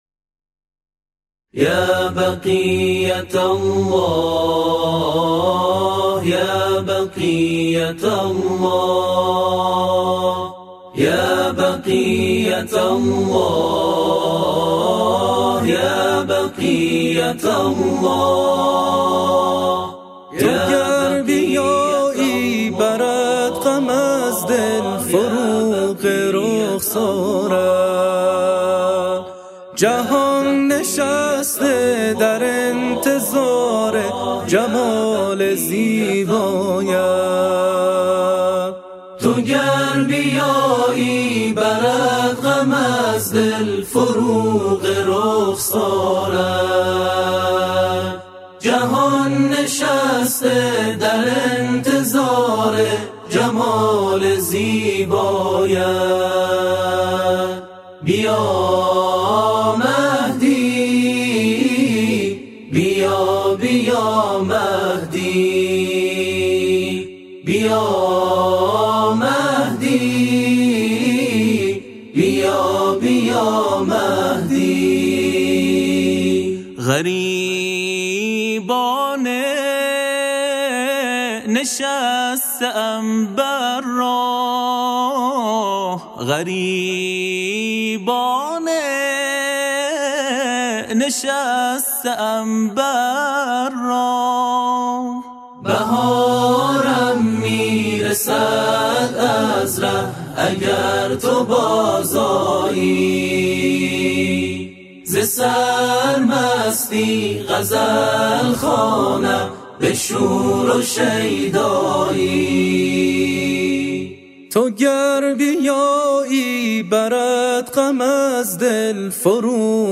تواشیح